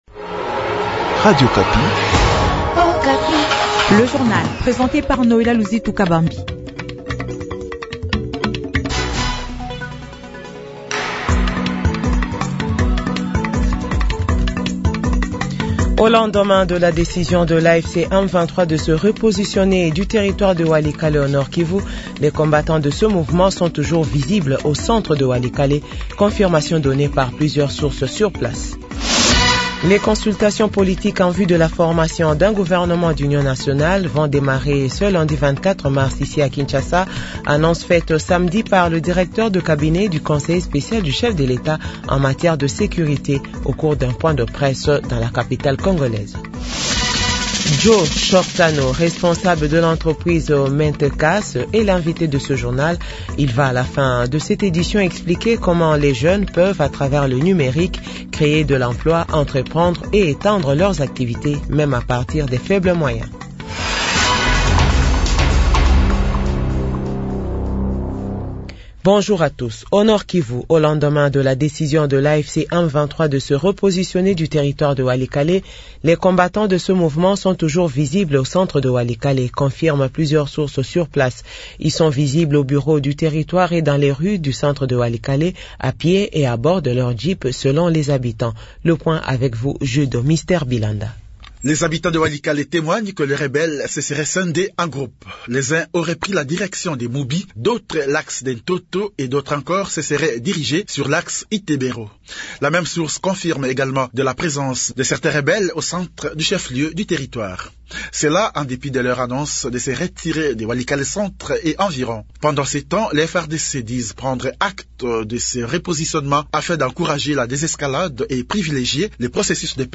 Jounal 6h-7h